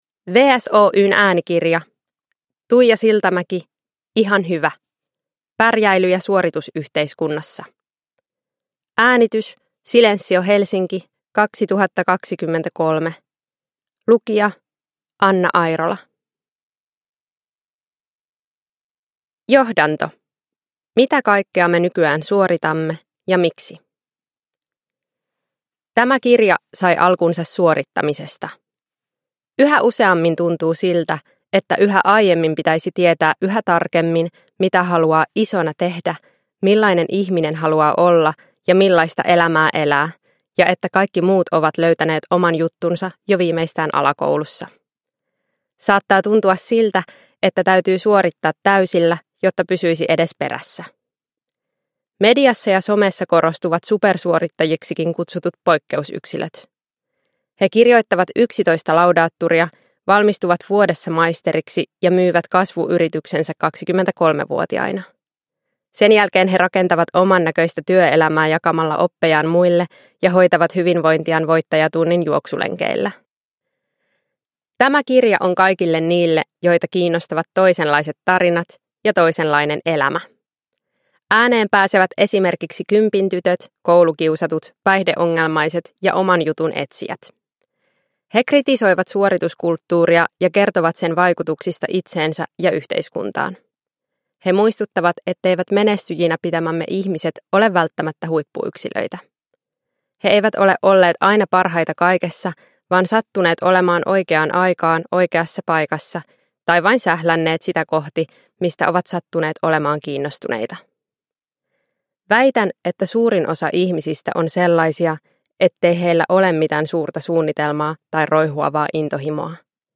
Ihan hyvä – Ljudbok – Laddas ner